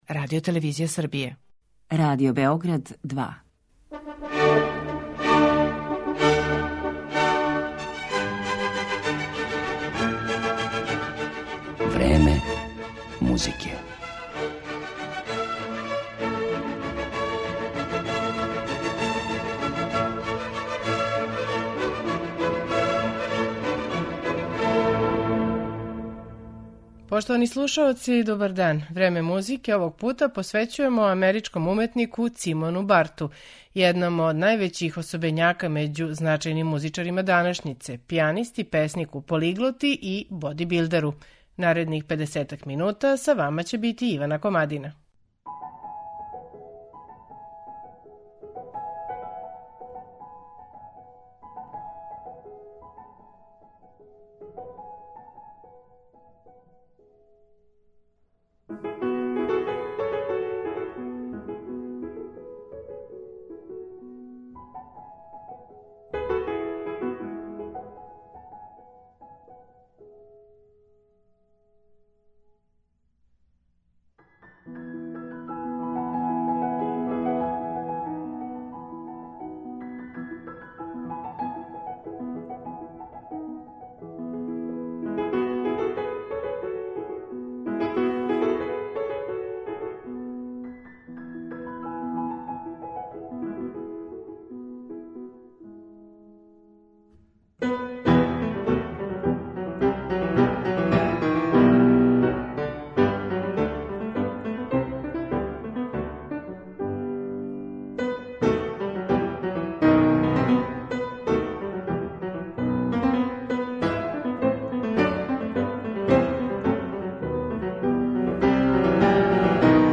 Цимон Барто, клавир